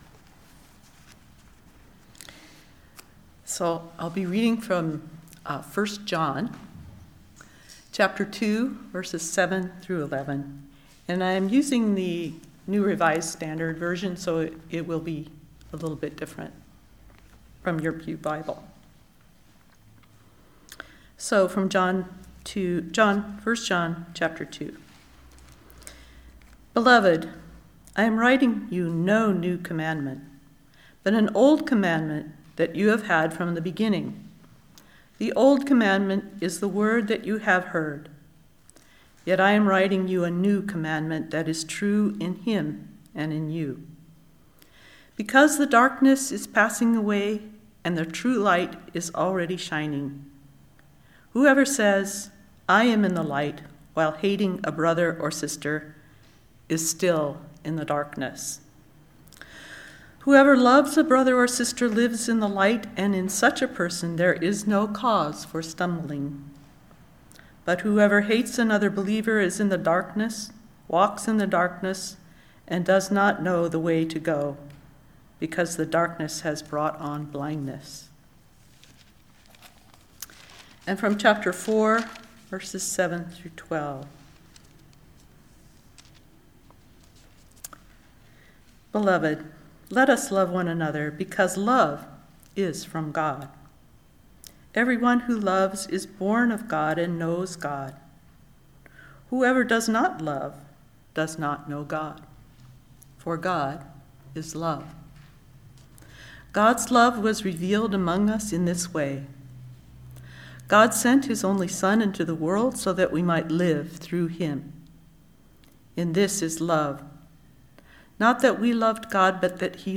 Listen to the most recent message, “Love after Hate,” from Sunday worship at Berkeley Friends Church.